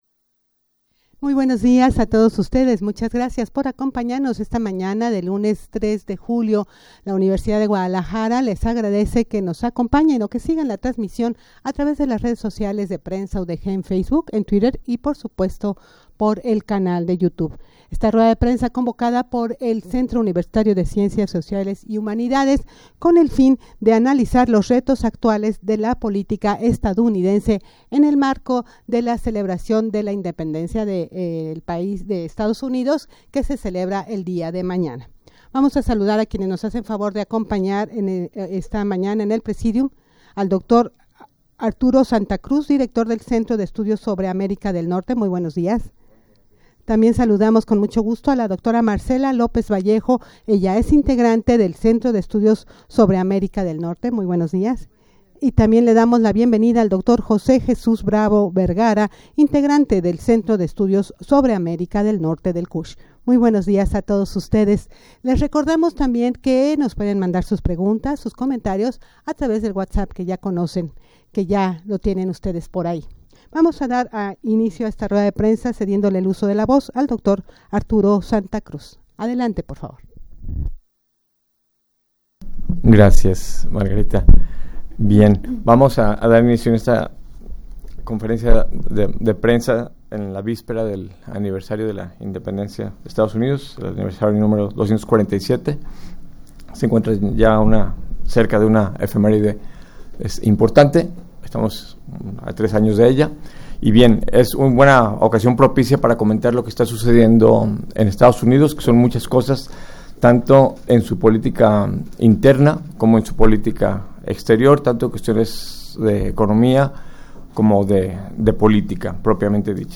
rueda-de-prensa-en-la-que-se-analizaran-los-retos-actuales-de-la-politica-estadounidense.mp3